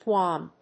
The name is pronounced choo-um (IPA: /ˈtʃuːəm/).